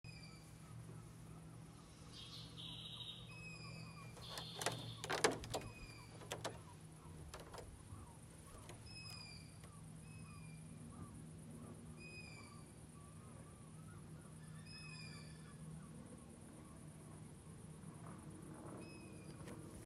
Fugl med lang pip
Befinner meg i Sandefjordområdet ved vannet. Det er en fugl som lager vedlagt lyd og vi hører den kun på kvelden.
Det er kun én ensformig lyd hele tiden.
Dette er tiggelyder fra hornugleunger.